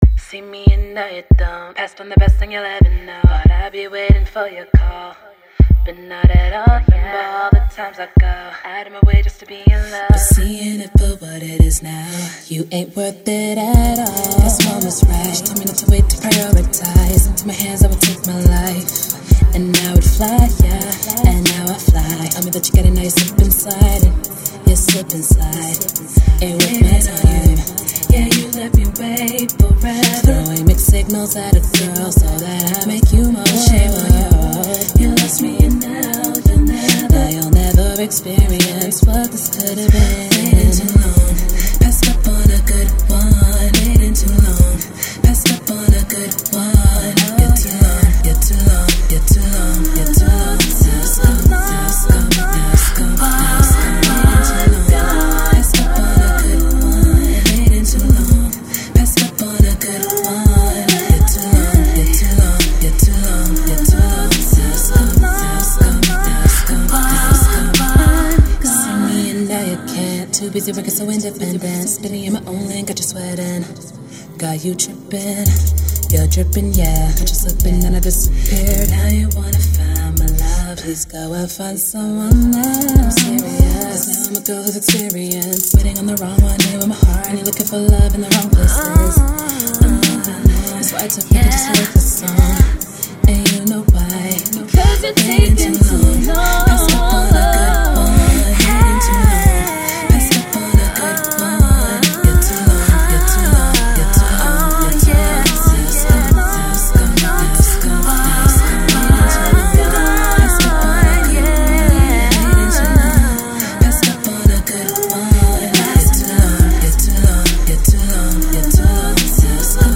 new R&B